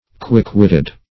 Quick-witted \Quick"-wit`ted\, a.